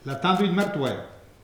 Langue Maraîchin
Patois - ambiance
Catégorie Locution